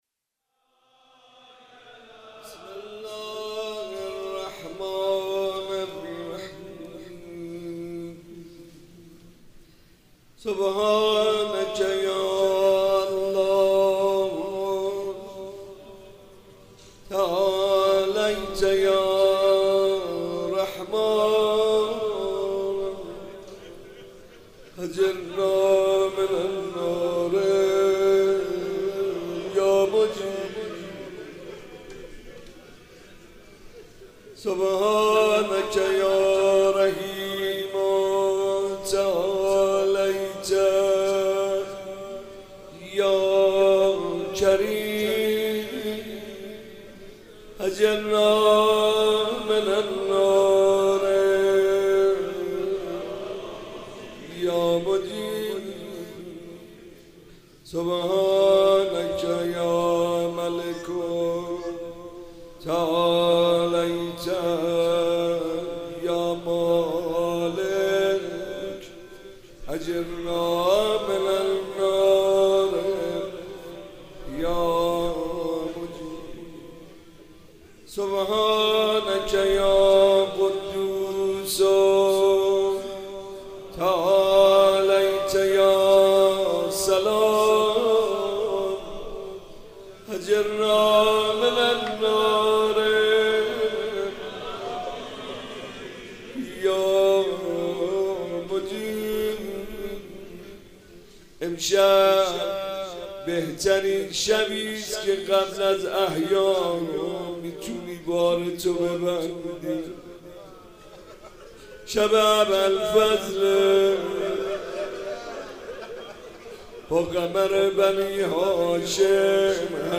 شب چهاردم رمضان
مناجات